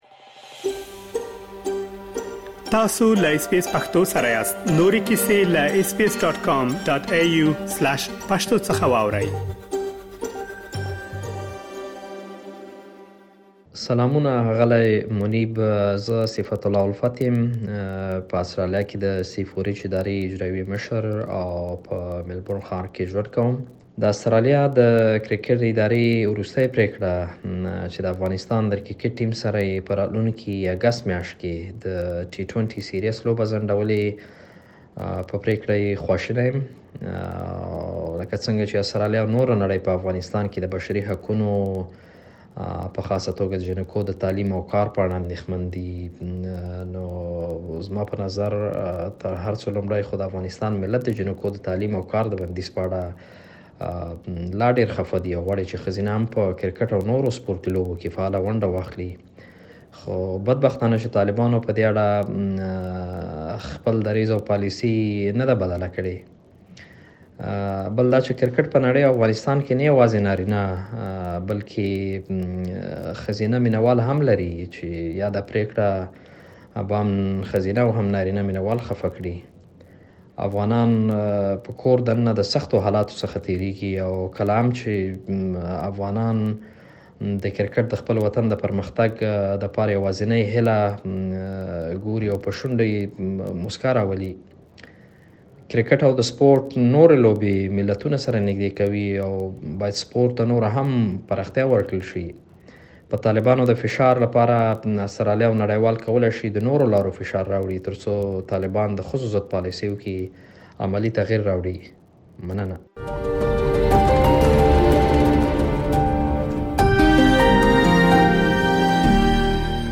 د همدغې موضوع په اړه یو شمېر افغانانو له اس بي اس سره خپل نظرونه شریک کړي چې په رپوټ کې یې اورېدلی شئ.